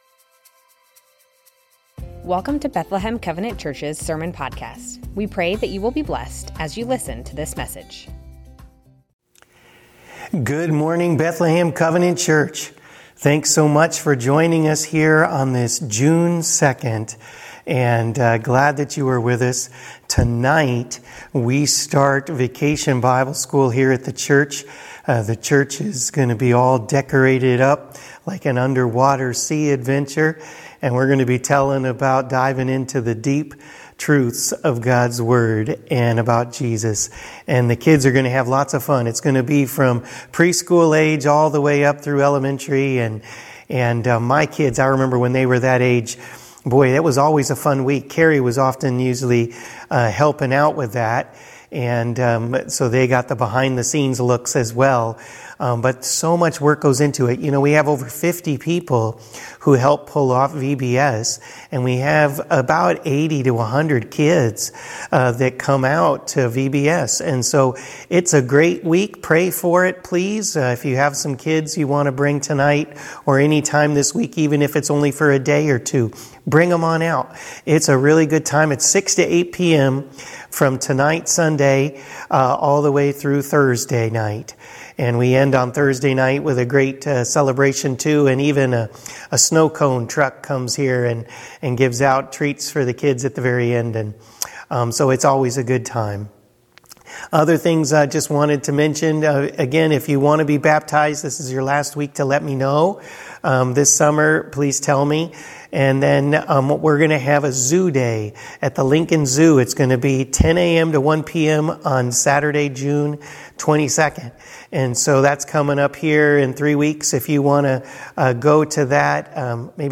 Bethlehem Covenant Church Sermons James - The words of my mouth Jun 02 2024 | 00:30:28 1x 00:00 / 00:30:28 Subscribe Share Spotify RSS Feed Share Link Embed